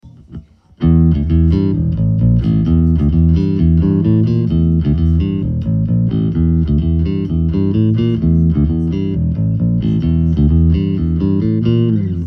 tak dámy a pánové trochu sem zapracoval na nahrávání viměnil struny na moje ATK jsem dal šechno naplno tak bych chtěl slyšet znovu  váš názor